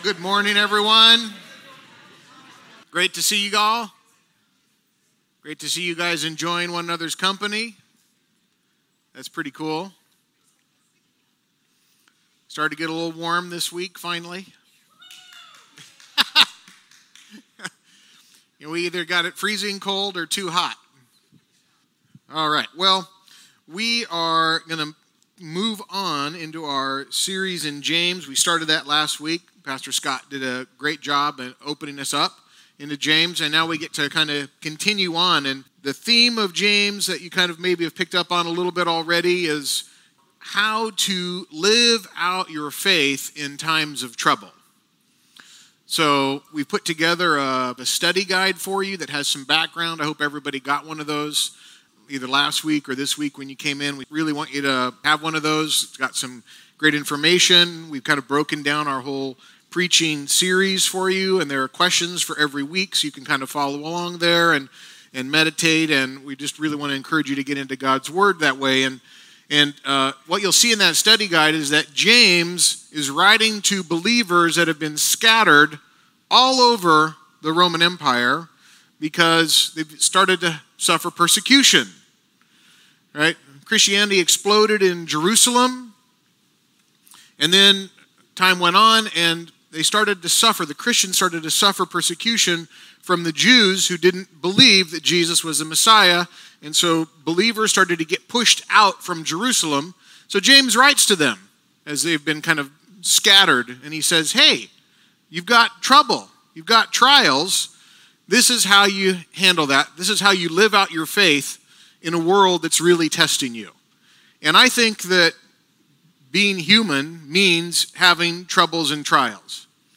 April 23 service video about everyday faith.